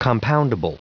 Prononciation du mot compoundable en anglais (fichier audio)
Prononciation du mot : compoundable